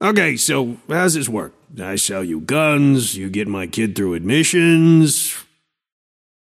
Shopkeeper voice line - Okay, so how does this work. I sell you guns, you get my kid through admissions…?